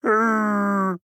AV_bear_howl.ogg